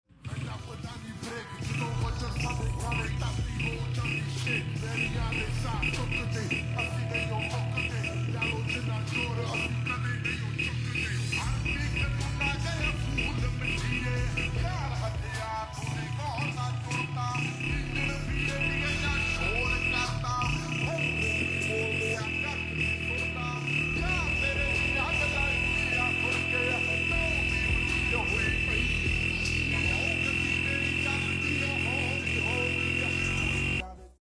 Whistler After 3 Months Use sound effects free download
Whistler After 3 Months Use Same Sound Long Term Review